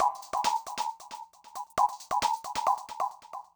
drums03.wav